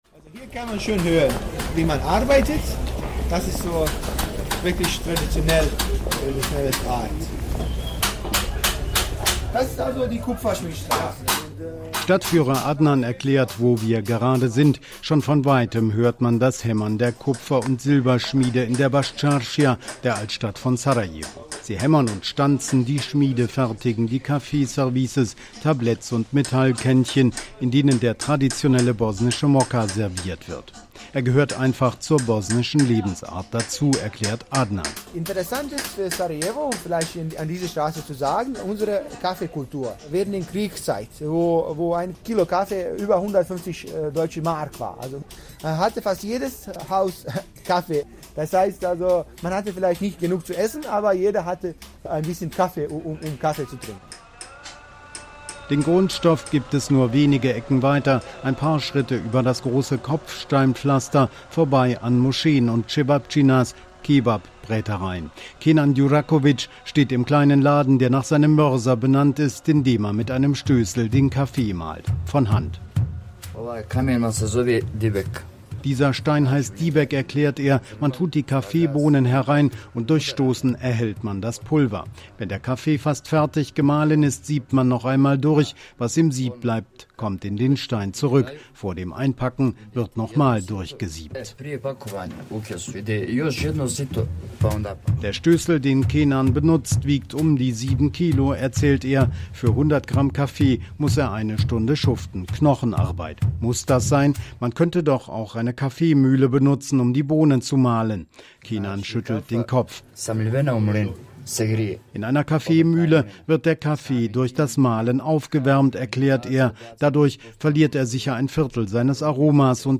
Überall hört man das Hämmern der Kupferschmiede, von weitem ruft der Muezzin zum Gebet.
Besondere-Märkte-Bascarsija-Altstadt-von-Sarajevo.mp3